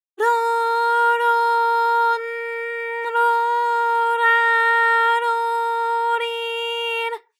ALYS-DB-001-JPN - First Japanese UTAU vocal library of ALYS.
ro_ro_n_ro_ra_ro_ri_r.wav